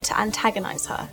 Again, we find that similar qualities are still alive and well in London/Estuary. Here’s Kirk Norcross from Essex saying older, singer, this year: